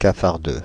Ääntäminen
US : IPA : [ˈɡluː.mi]